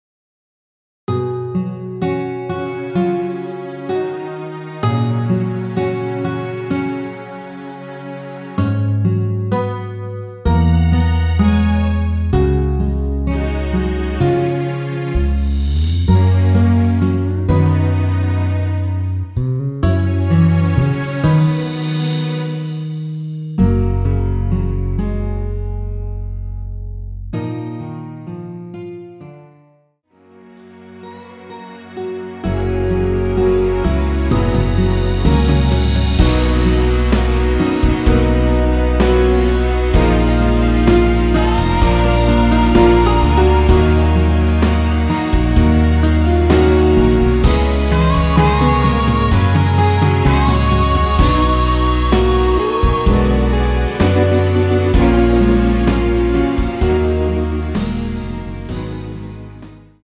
원키에서(-2)내린 MR입니다.
F#
앞부분30초, 뒷부분30초씩 편집해서 올려 드리고 있습니다.
중간에 음이 끈어지고 다시 나오는 이유는